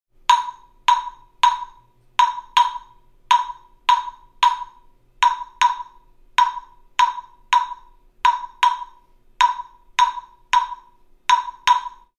LP Clave - Standard Exotic Hardwood (LP211R)
LP Standard Clave is larger than the LP Traditional Clave and is crafted of kiln dried exotic hardwood. In this set, the clave (10" L x 1 7/8" W) is larger than the striker (10" L x 3/4" W) and it features a cut-away sound chamber designed to increase volume and vary the sound qualities.